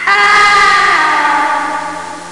Download Falling sound effect for video, games and apps.
Falling Sound Effect
falling-1.mp3